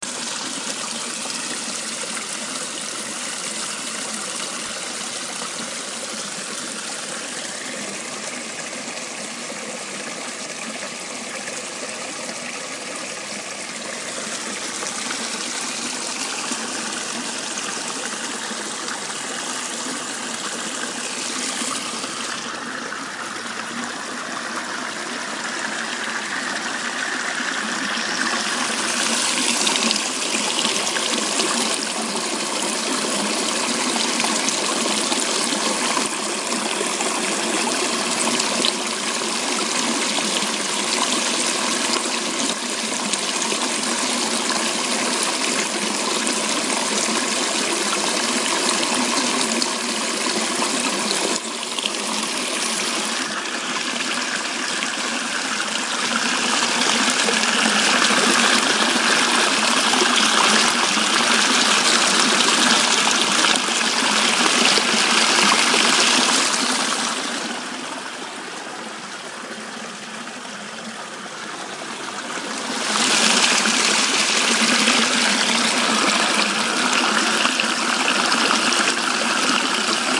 小溪欢快的流淌声
描述：流水声
标签： 急促 水流 小溪
声道立体声